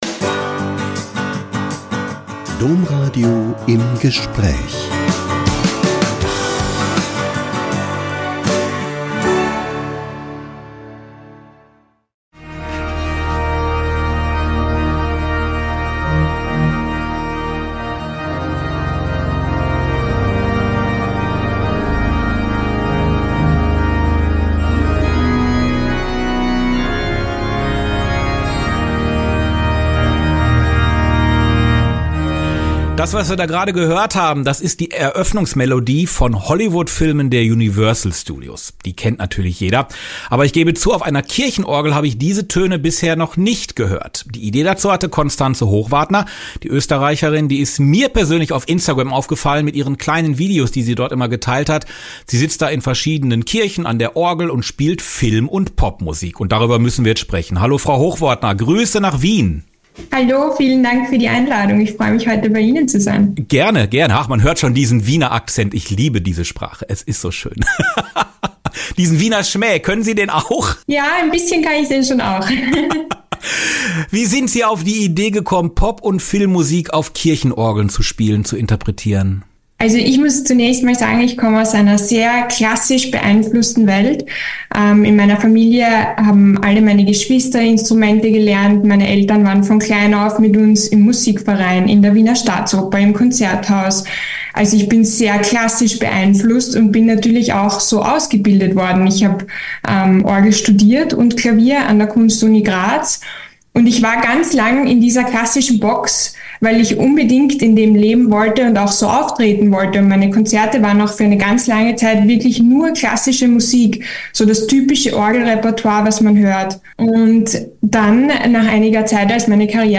~ Im Gespräch Podcast